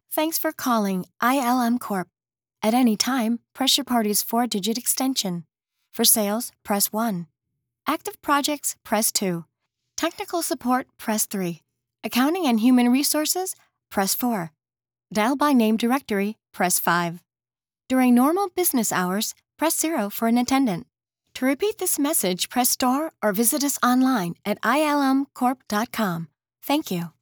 Commercial
I have a professional home studio that delivers pristine audio.
Voice Style: Sultry, Conversational, Energetic, Youthful, Real Person, Attractive, Fun, Natural